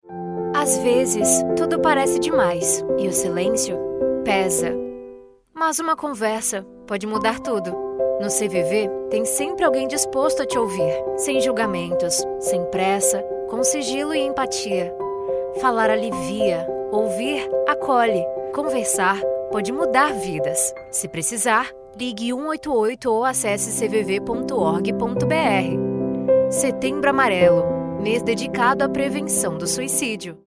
Spot